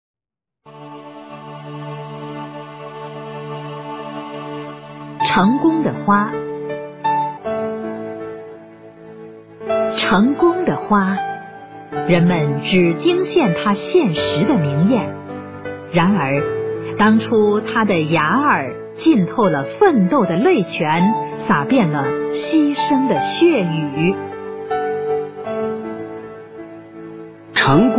九年级语文下册音频素材：《成功的花》女声配乐朗读